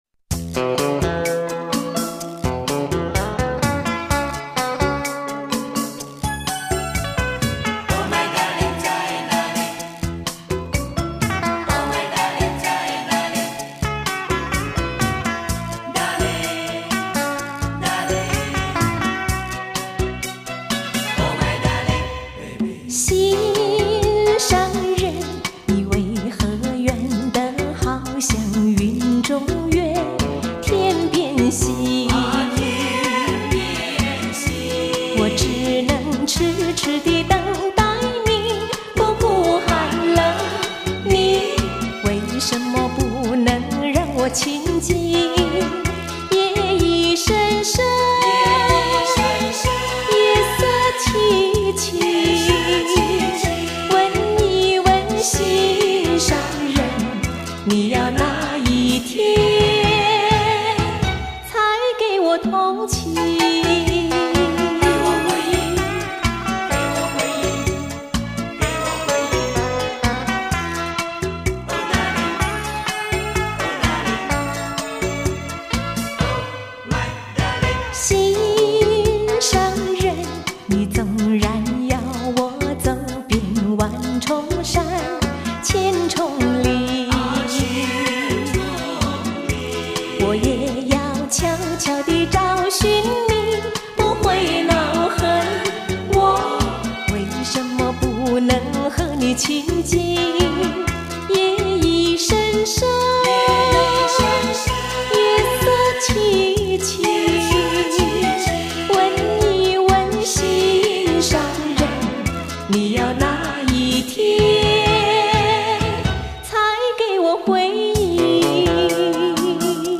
美音歌后